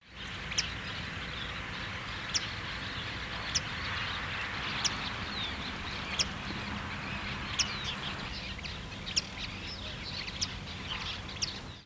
Many-colored Rush Tyrant (Tachuris rubrigastra)
Life Stage: Adult
Detailed location: Laguna
Condition: Wild
Certainty: Observed, Recorded vocal
Tachuri_siete-colores.mp3